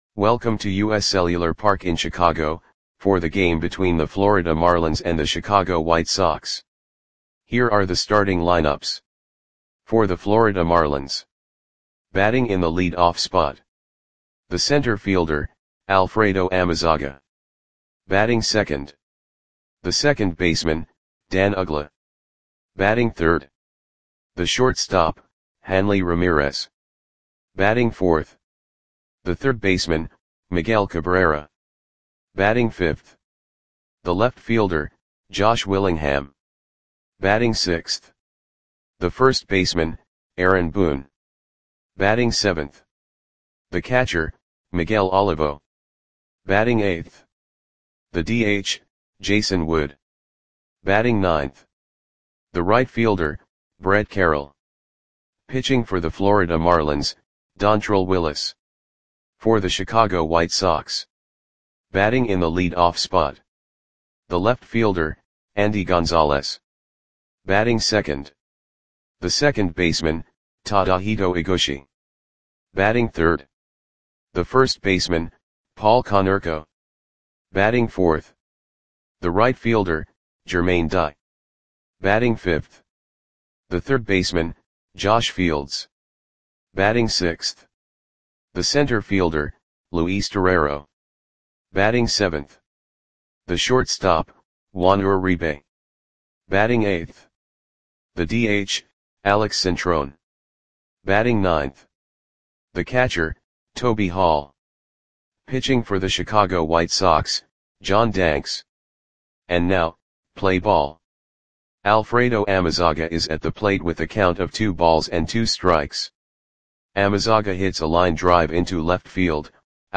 Audio Play-by-Play for Chicago White Sox on June 19, 2007
Click the button below to listen to the audio play-by-play.